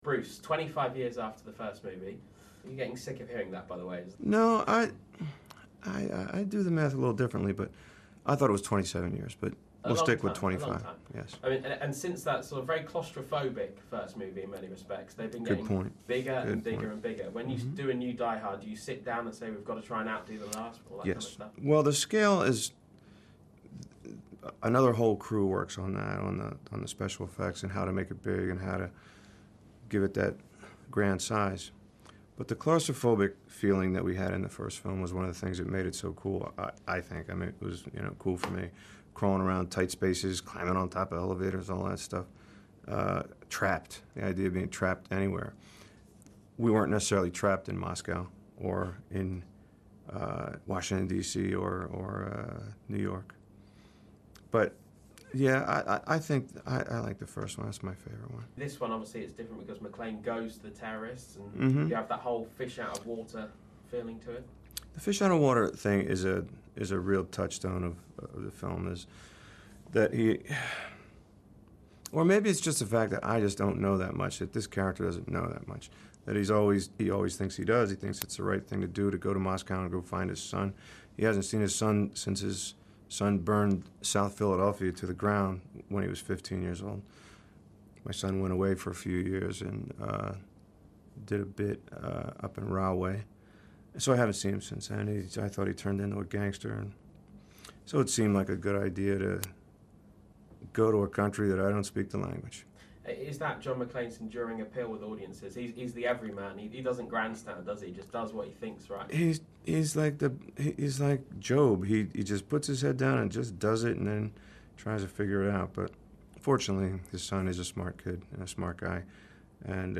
Bruce Willis talks to Sky News Radio about A Good Day to Die Hard